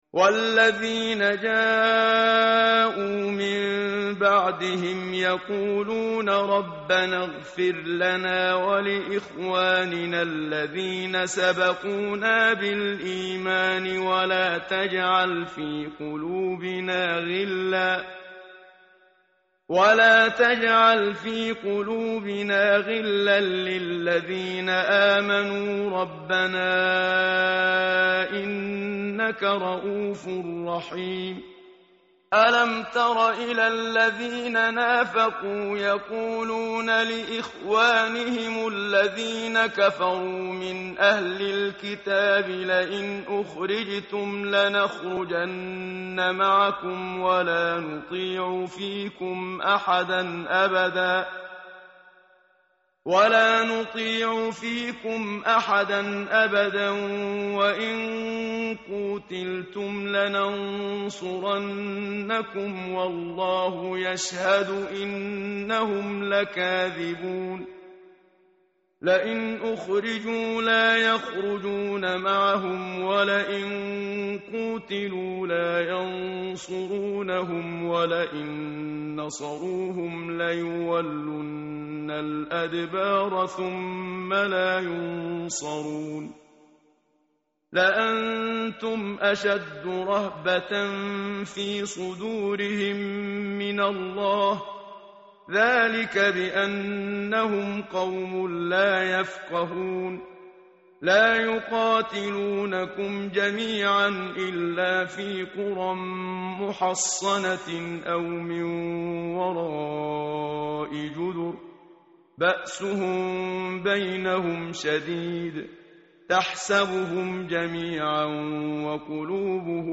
متن قرآن همراه باتلاوت قرآن و ترجمه
tartil_menshavi_page_547.mp3